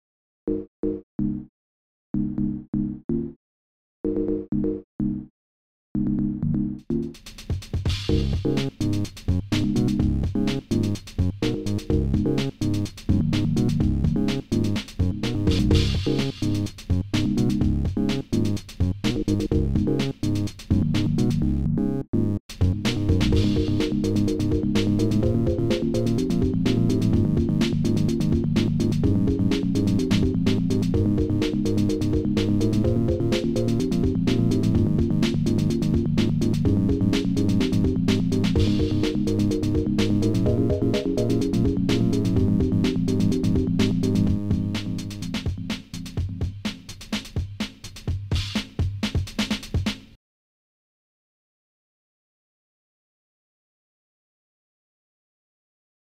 now it has a bassline! hooray!